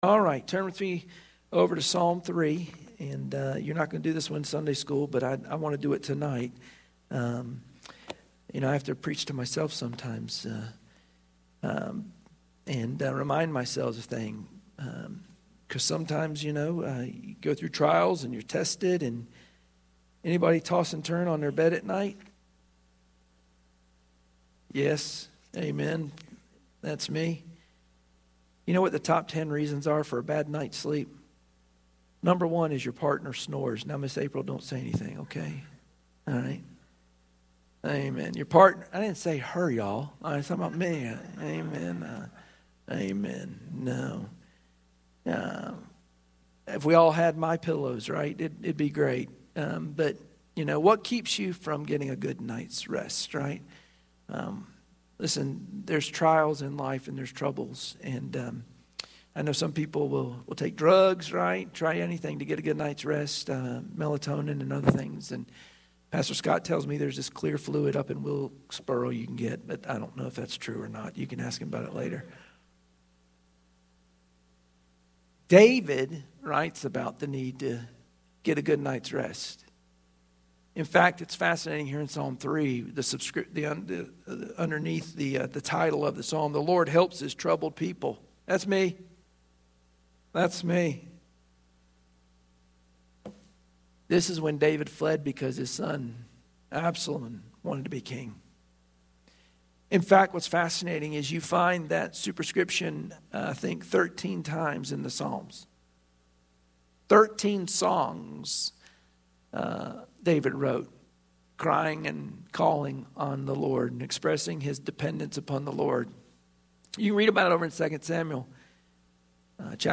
Wednesday Prayer Mtg Passage: Psalm 3 Service Type: Wednesday Prayer Meeting Share this